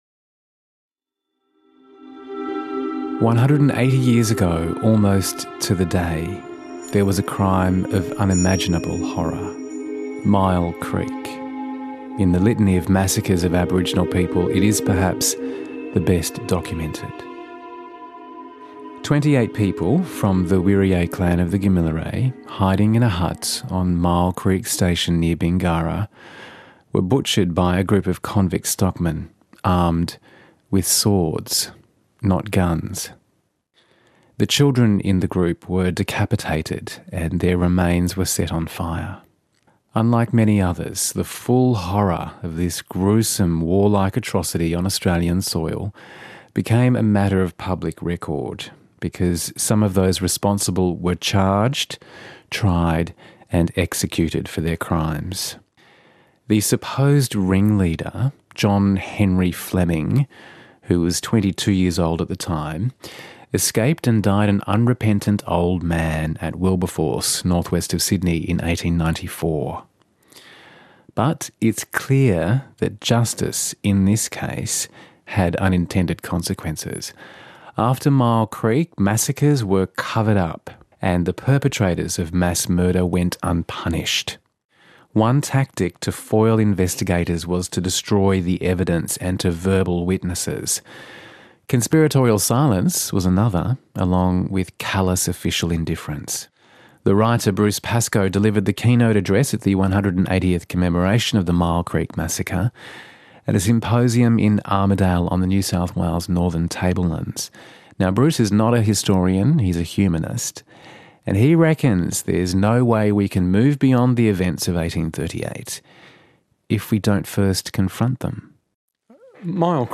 A symposium was held to mark the 180th anniversary of the massacre and the writer Bruce Pascoe delivered the keynote address.